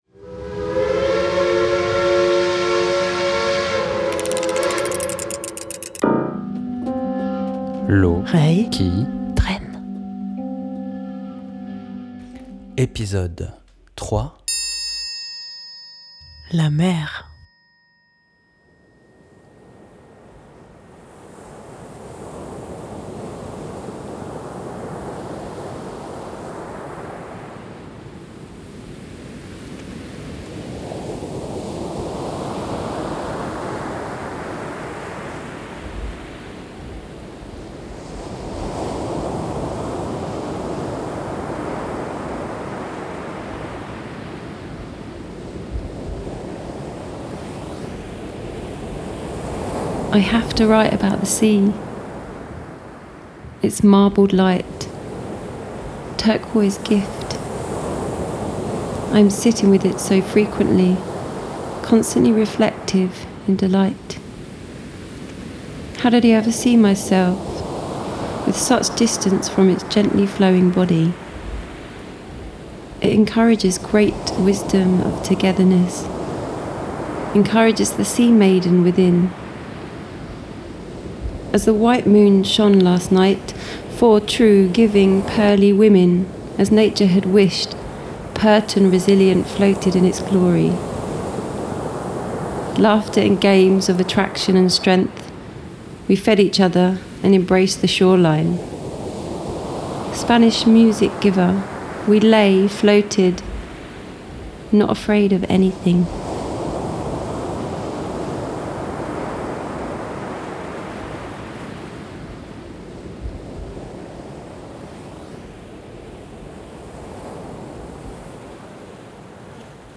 Promenade sonore dans l'univers de la mer
Gare, place de village, église, supermarché, immeuble désaffecté, parc, plage...
Armés de leurs enregistreurs, ils captent les ambiances de ces lieux et se laissent porter par l’inspiration pour en proposer une lecture poéticodramaticomusicale à leur sauce.
Captations : Pornic (France) - Aberdyfi (Pays de Galles) - Cardiff Bay (Pays de Galles)